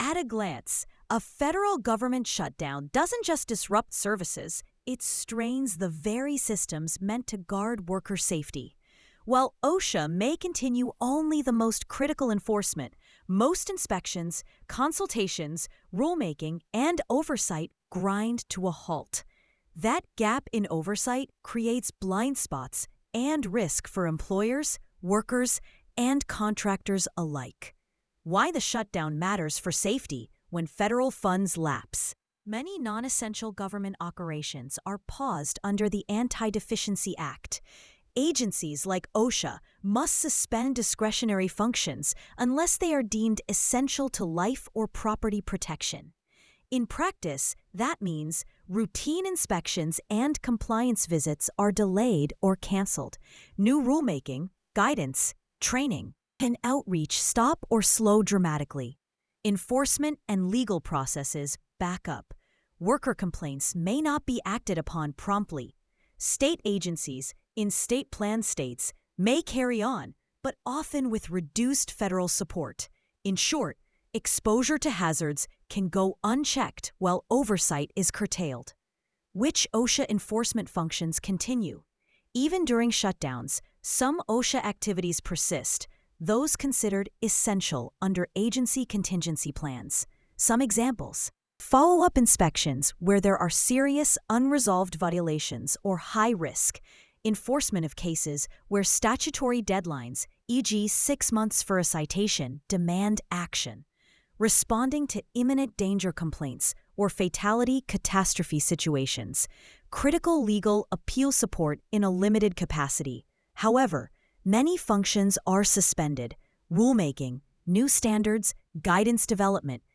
sage_gpt-4o-mini-tts_1x_2025-10-06T16_27_30-241Z.wav